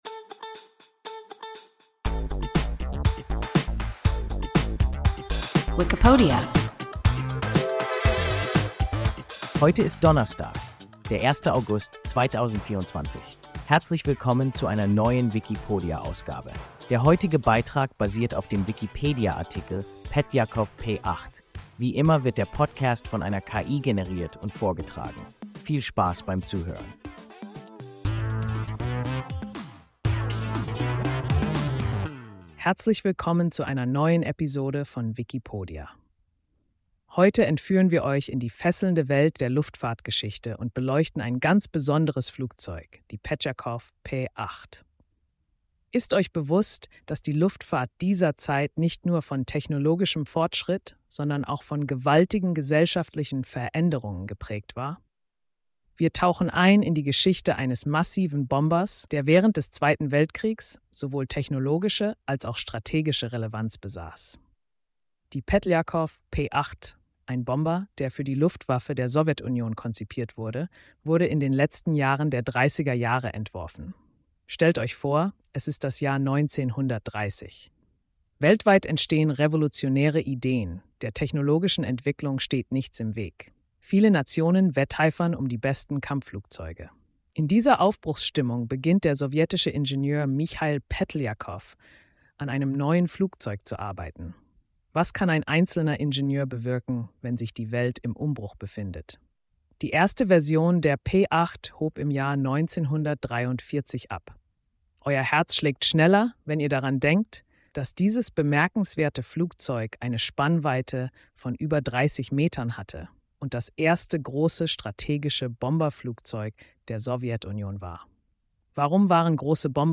Petljakow Pe-8 – WIKIPODIA – ein KI Podcast